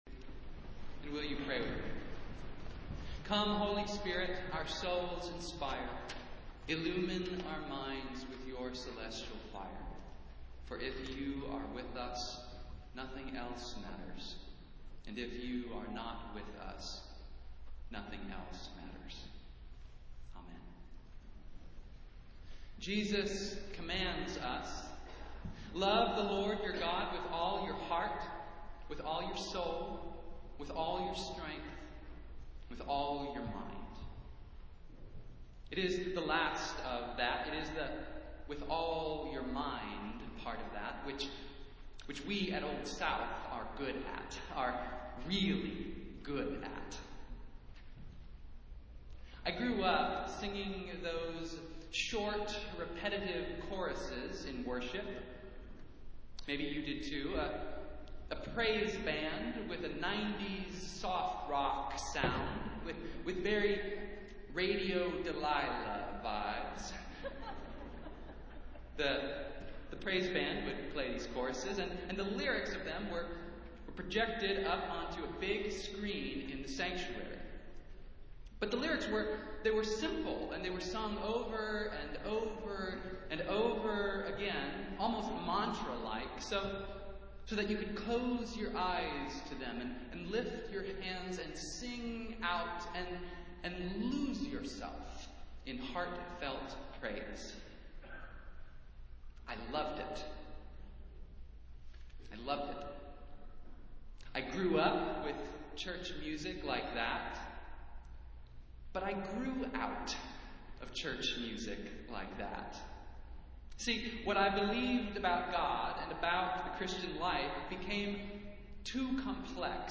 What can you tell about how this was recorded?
Festival Worship - Fourth Sunday in Lent